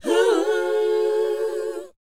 WHOA E D.wav